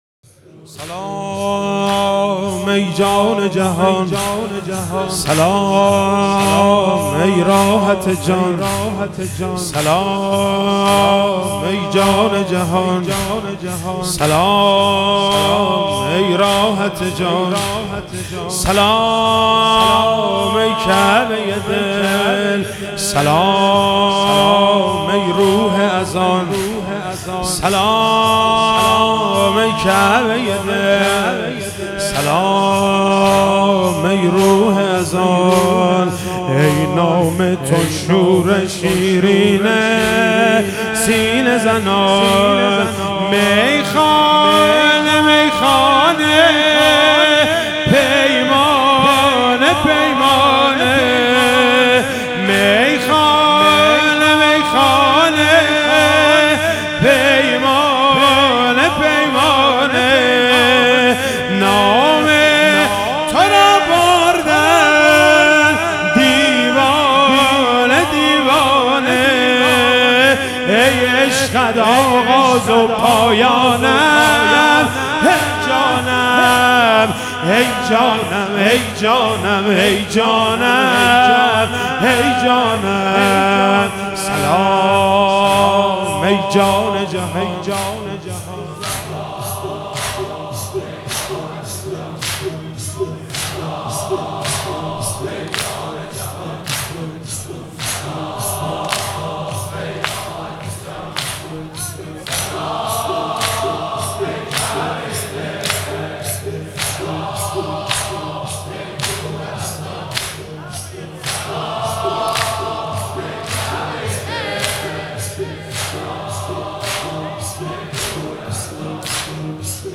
شب هفتم محرم 97 - زمینه - سلام ای جان جهان سلام